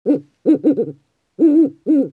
owl2.mp3